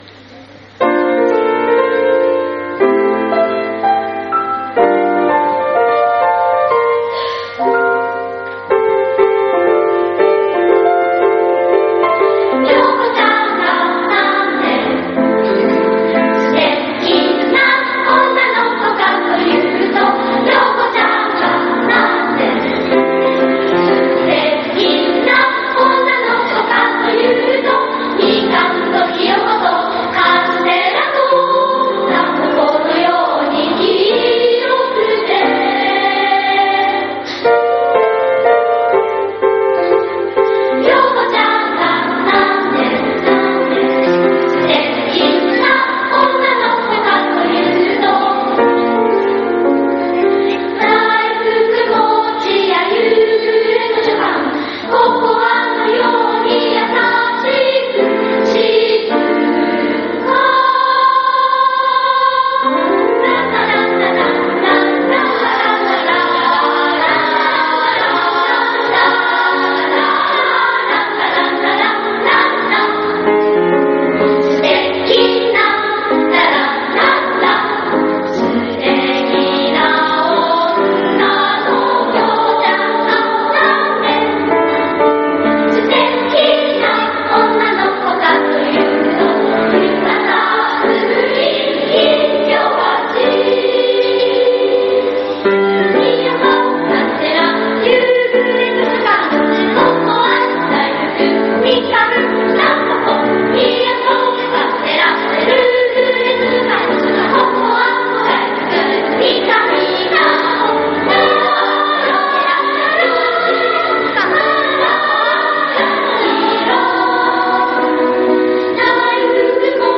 １０月５日（金）に体育館で行われた合唱部ミニ発表会の録音を紹介します。
本日昼休み、合唱部のミニ発表会が体育館で行われました。
きれいな歌声に全校児童もシーンとなって聞いていました。